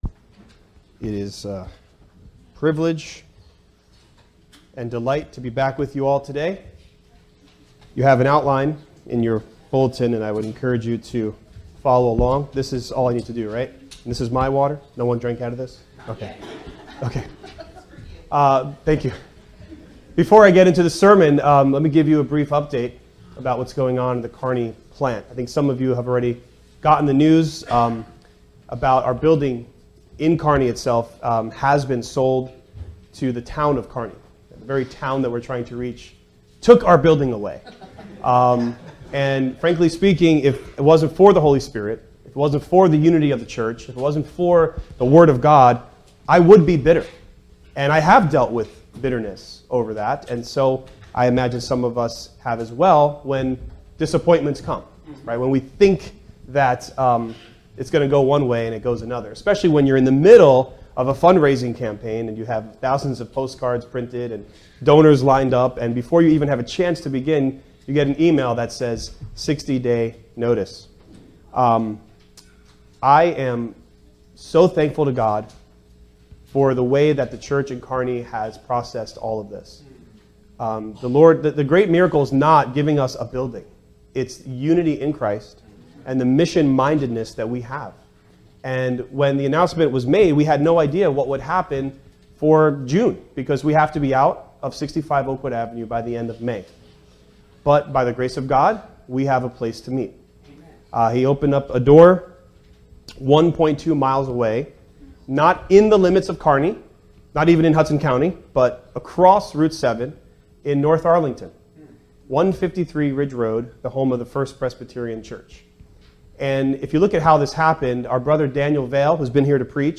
Fervor and Accuracy | SermonAudio Broadcaster is Live View the Live Stream Share this sermon Disabled by adblocker Copy URL Copied!